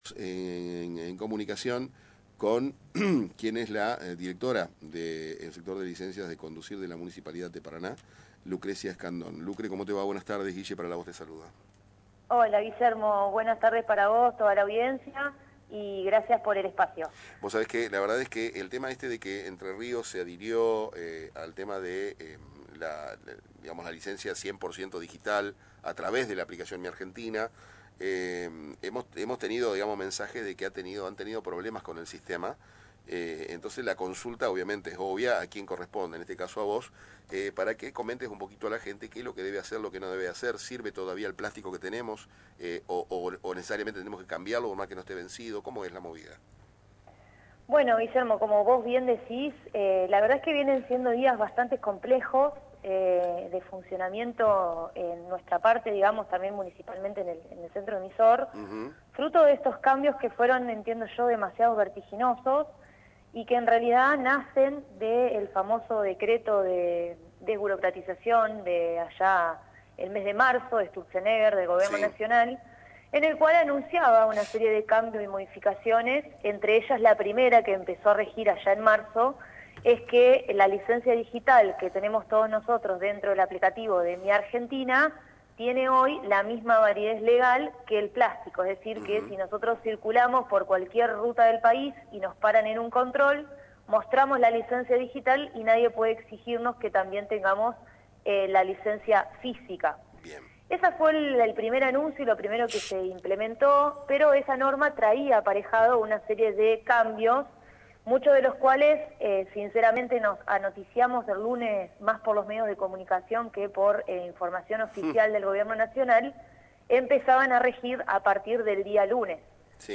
Así lo explicó la funcionaria Lucrecia Escandón, en una entrevista donde abordó el impacto de los recientes cambios nacionales y su implementación a nivel local.